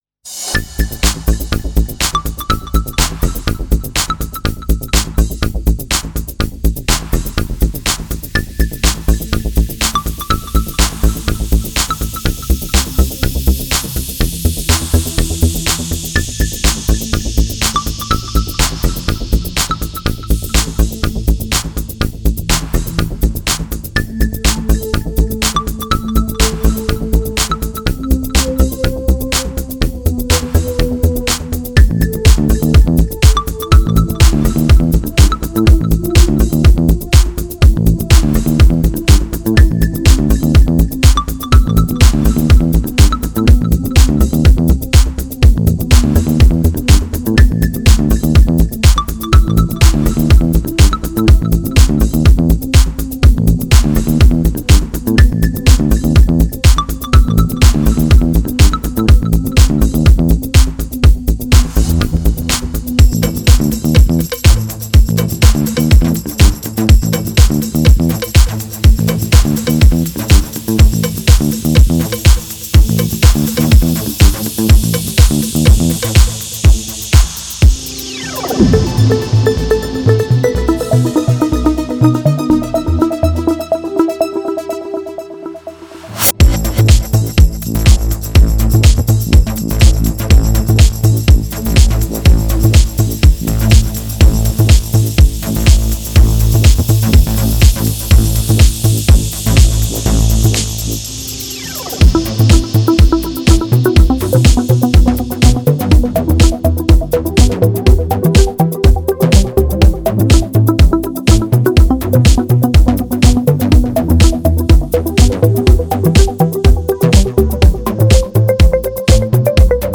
Piano + electronic music = best pairing ever.